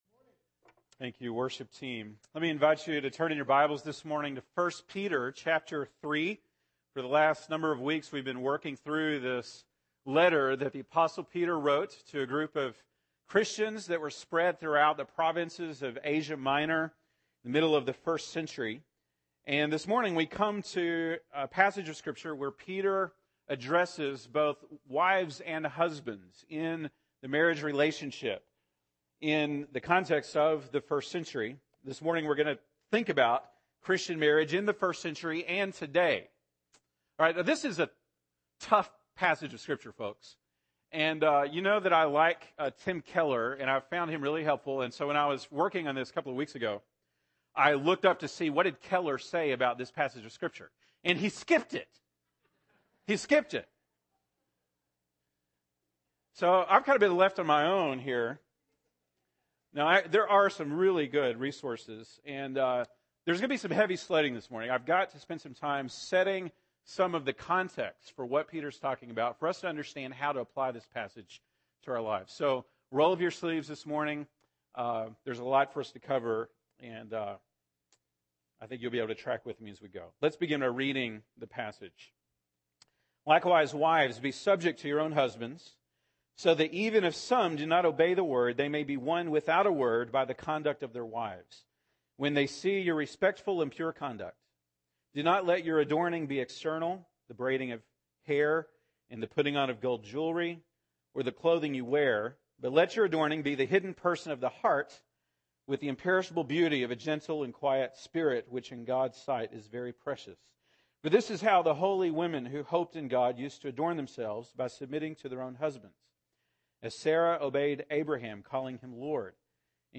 September 27, 2015 (Sunday Morning)